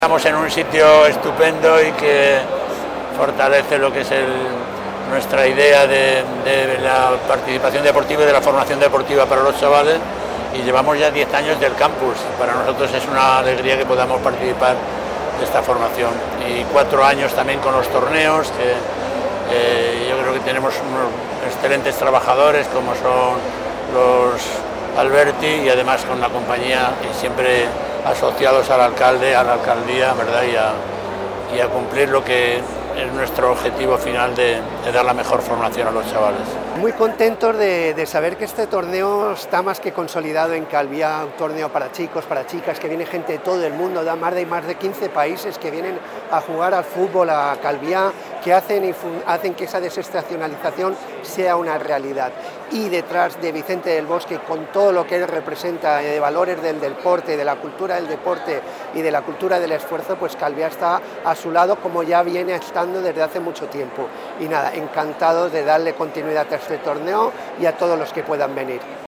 vicente-del-bosque-and-the-mayors-statements.mp3